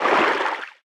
Sfx_creature_seamonkeybaby_swim_fast_03.ogg